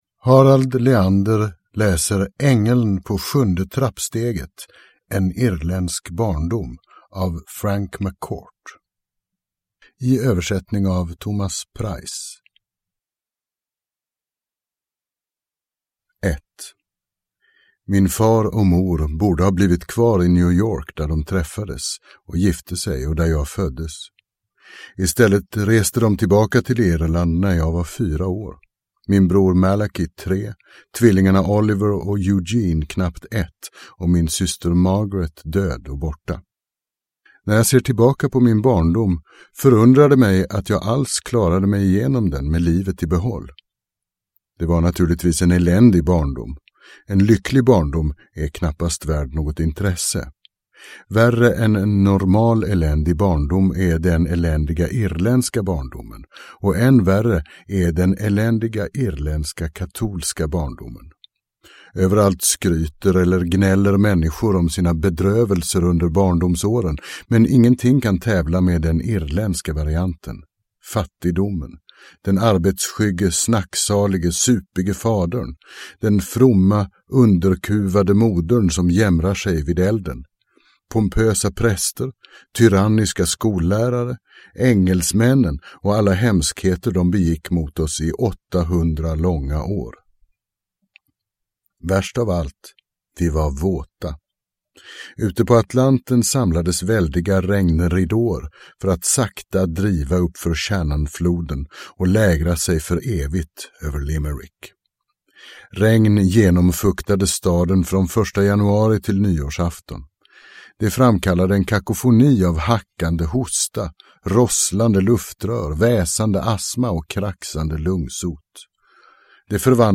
Ängeln på sjunde trappsteget – Ljudbok – Laddas ner